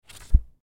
Download Book CLose sound effect for free.
Book Close